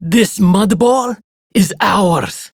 BbPatrolPrimeGrineerFemale0300_en.ogg